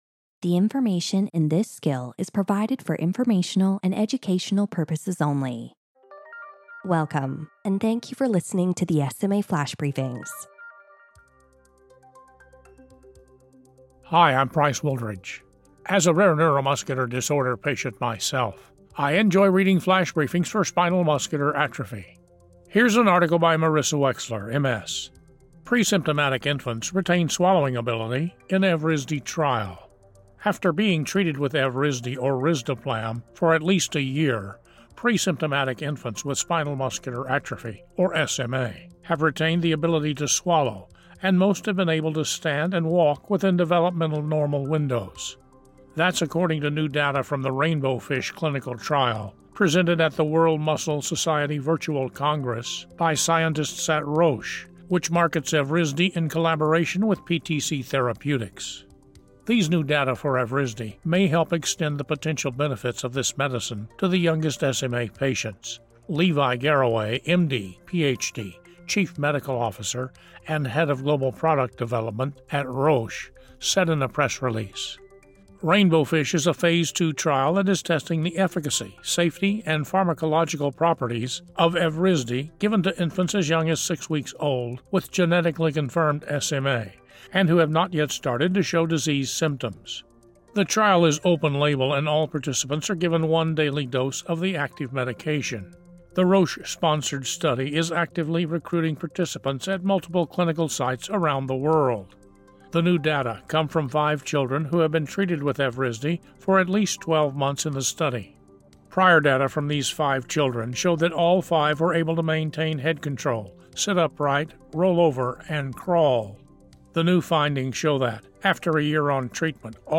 SMA News & Perspectives